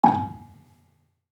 Gambang-G#4-f.wav